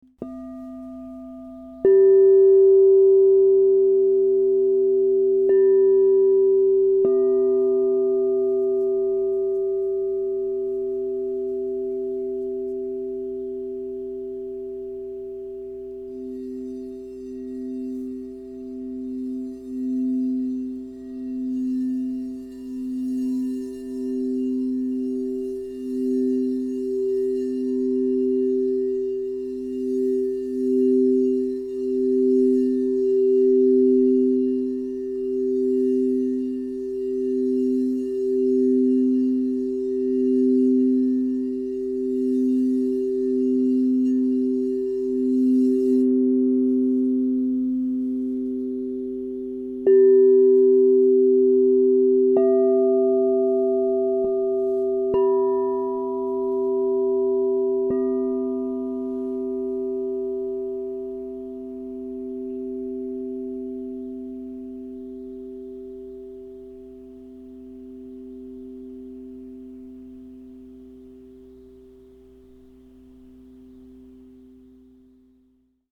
This 2 Bowl Crystal Tones® alchemy singing bowl harmonic set includes:Violet Aura Gold 10″ C -30 Crystal Tones® Singing BowlLapis Lazuli, Platinum 8″ G -15
Perfect Fifth Crystal Tones® Harmonic Singing Bowl Set